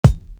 Luce Kick.wav